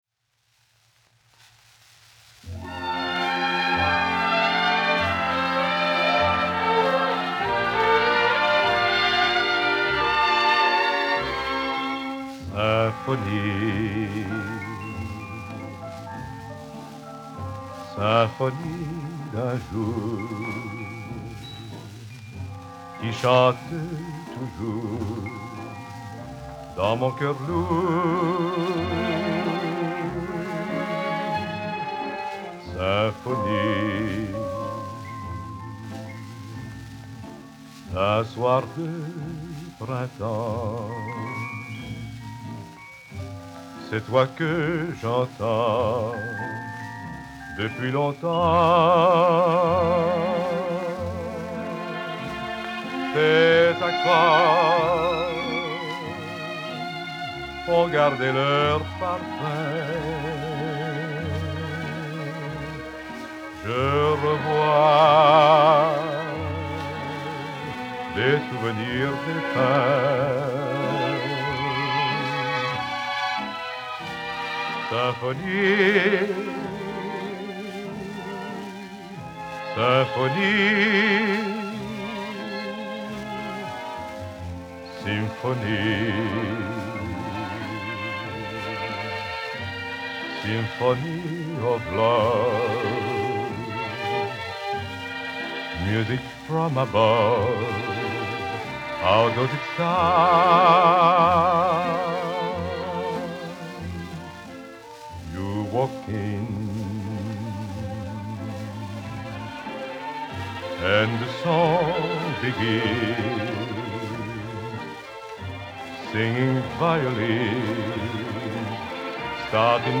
очень романтический стиль.